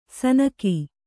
♪ sanaki